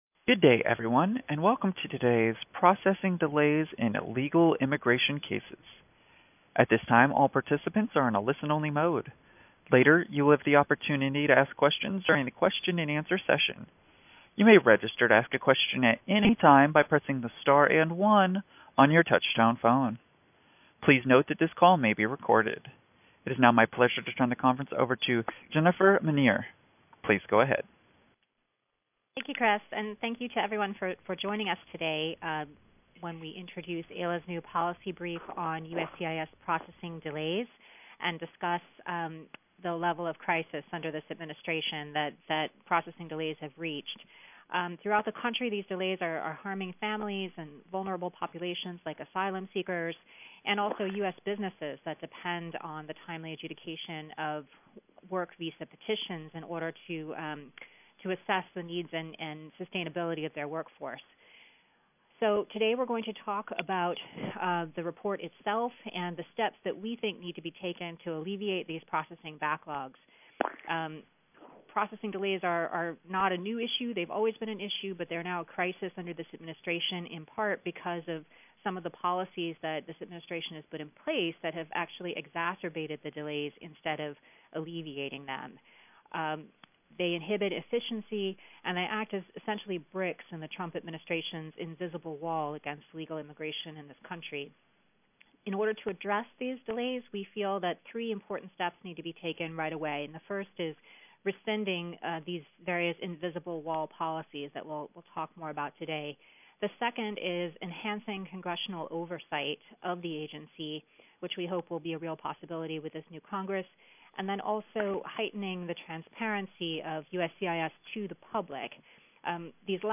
Audio from AILA’s Press Call on USCIS Processing Delays